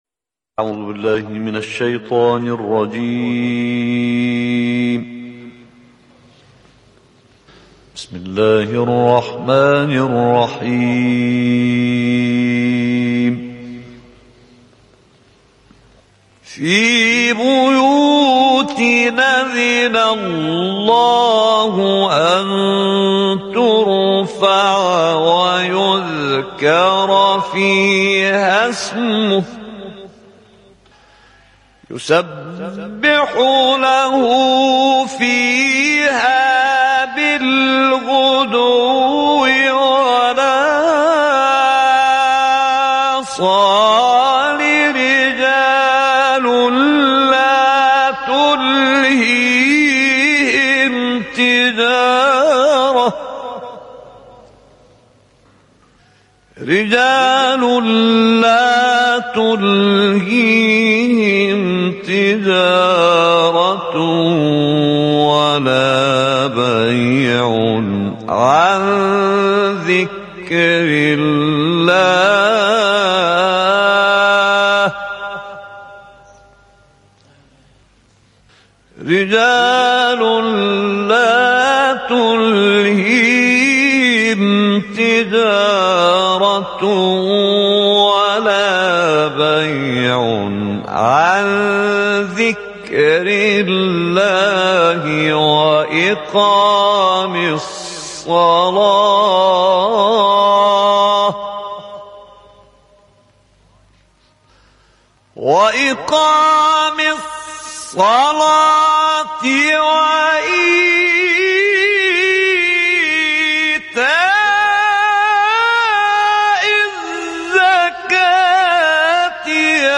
Suara kekal menarik dan berkuasa sehingga umur 88 tahun + Mp3
Seni membaca Al-Quran / 27
TEHRAN (IQNA) - Master Ahmad Muhammad Amir (احمد محمد عامر) adalah salah seorang qari terkemuka Mesir yang membaca dengan penuh kuasa dan sangat menarik walaupun berusia 88 tahun sebelum wafatnya.
Suara Ahmad Muhammad Amir adalah salah satu suara paling kuat yang pernah didengar dari qari Mesir. Walaupun pada usia 88 tahun, beliau mengaji dengan penuh kuasa.